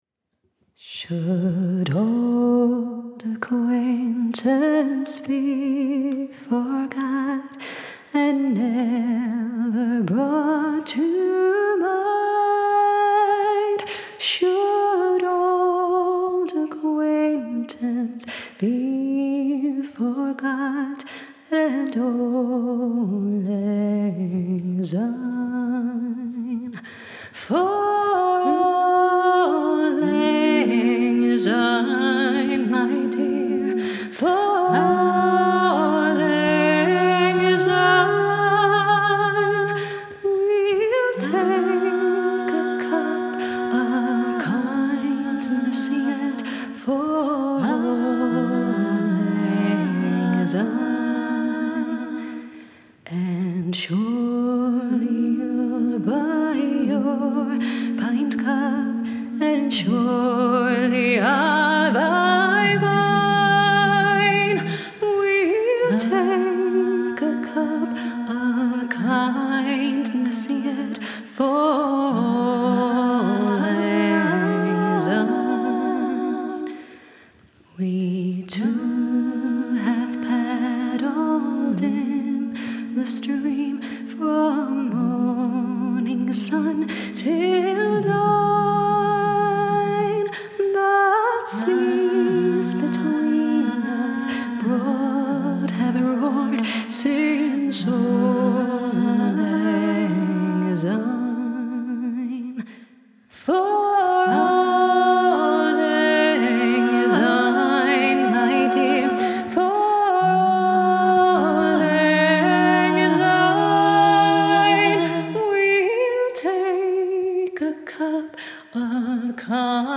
Text&musik: Robert Burns, traditionell skotsk folkvisa
Den här julmusiken är därför helt a capella, jag sjunger alla stämmor själv.